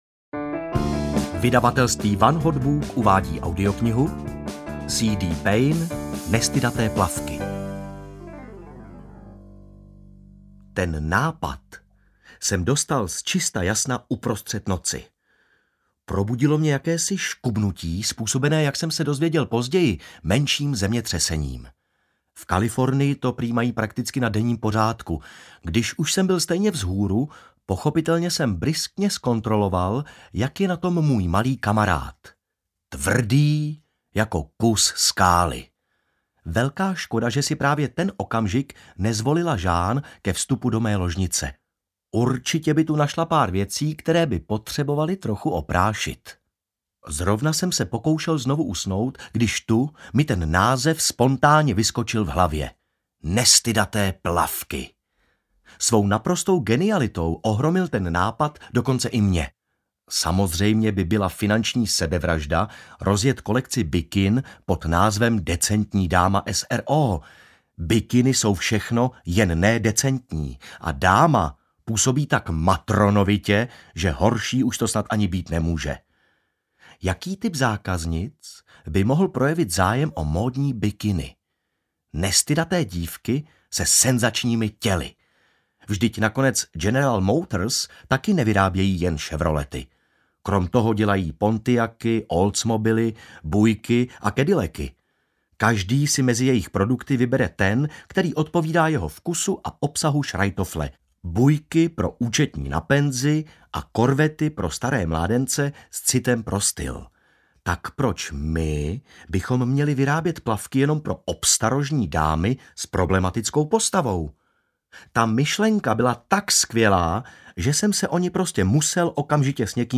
Nestydaté plavky audiokniha
Ukázka z knihy